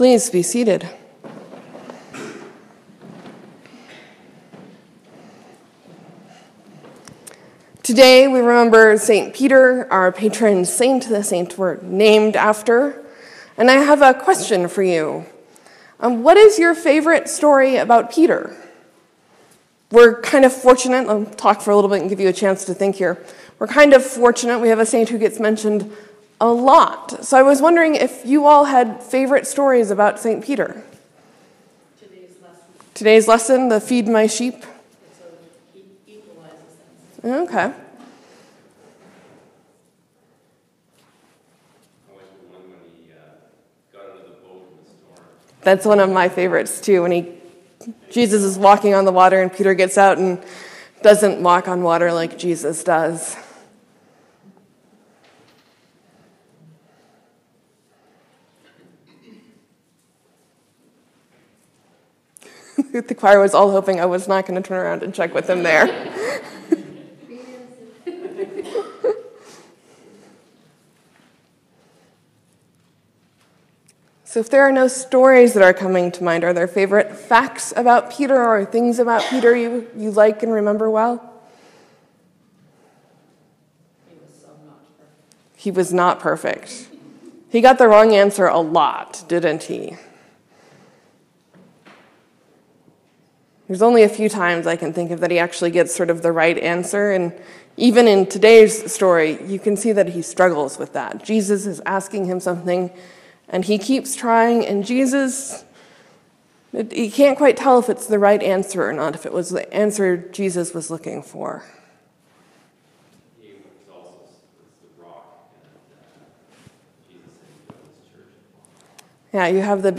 Sermon, , , Leave a comment
The sermon started with some interaction, and I tried to catch everything for the mic, and thank you for your patience.